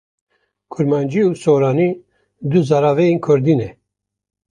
/kʊɾmɑːnˈd͡ʒiː/